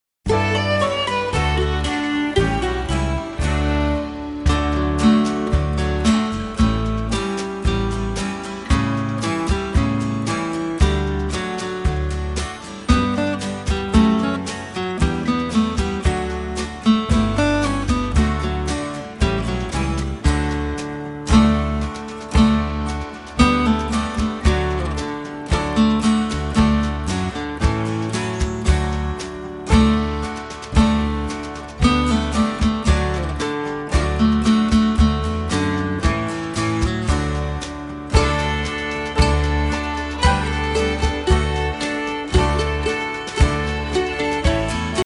Listen to a sample of the instrumental